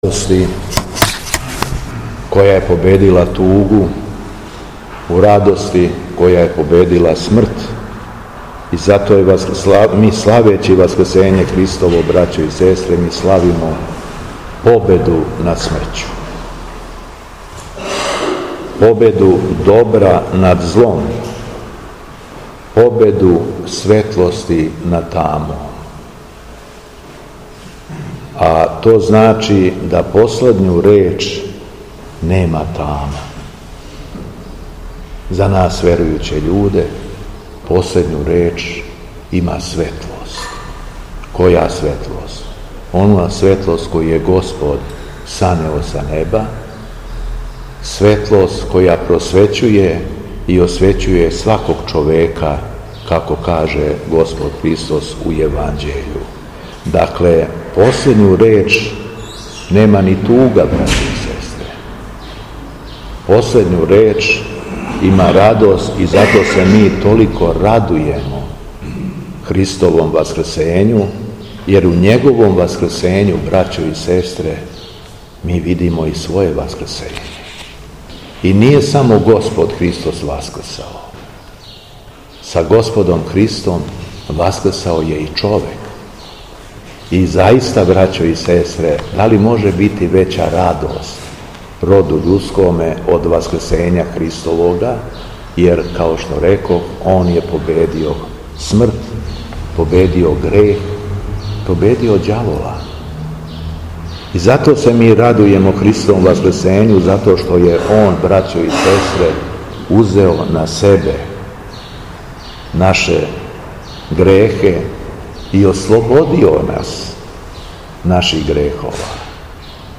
СВЕТА АРХИЈЕРЕЈСКА ЛИТУРГИЈА У СВЕТЛУ СРЕДУ У БЕЛОШЕВЦУ
Беседа Његовог Високопреосвештенства Митрополита шумадијског г. Јована
Након прочитаног зачала Светог јеванђеља по Јовану, архијереј се обратио окупљеним верницима, између осталог рекавши: